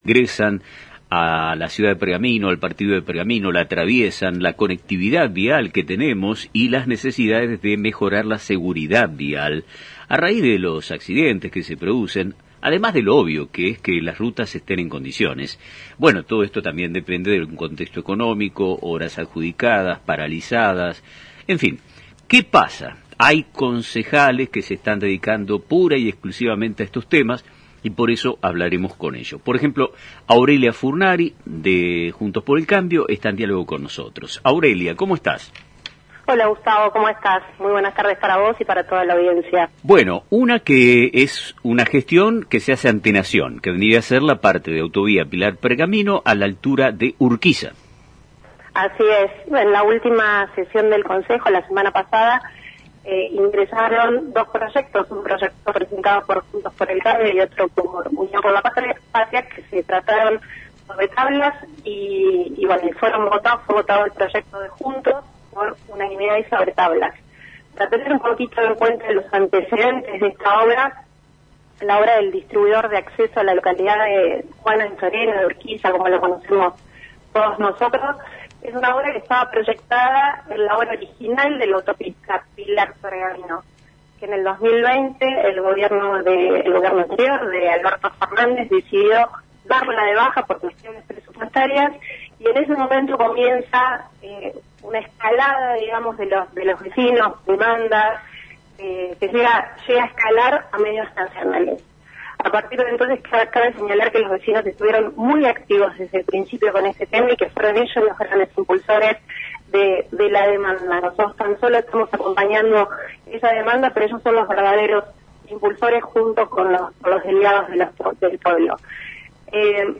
En una reciente entrevista en el programa «Nuestro Tiempo», la concejala Aurelia Furnari de Juntos por el Cambio detalló las acciones y preocupaciones en torno a varias obras viales cruciales en el partido de Pergamino, subrayando la necesidad de mejorar la seguridad vial y concluir proyectos importantes que han quedado inconclusos.